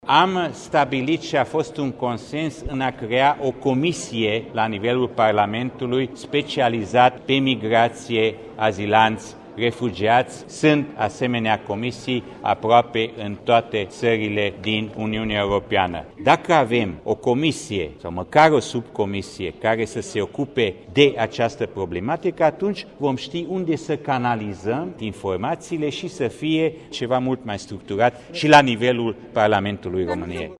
Anunţul a fost făcut de deputatul UDMR, Borbély László, preşedintele Comisiei de Politică Externă de la Camera Deputaţilor.